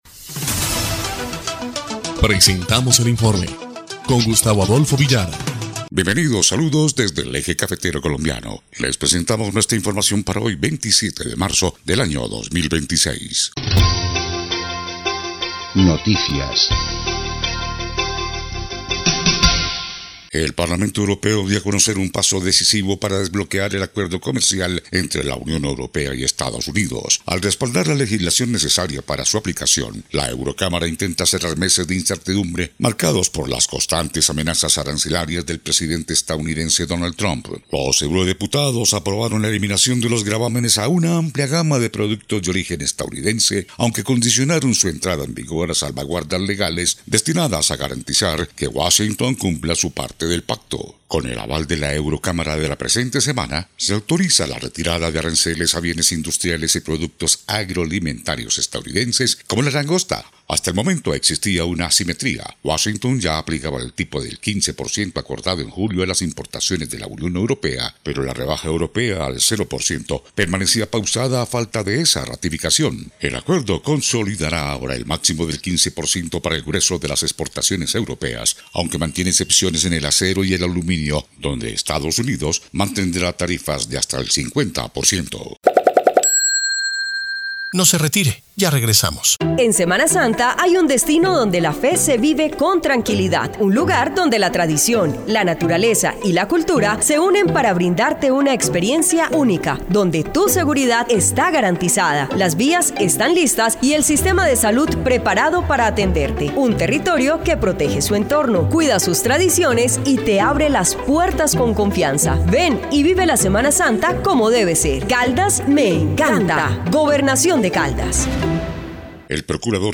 EL INFORME 2° Clip de Noticias del 27 de marzo de 2026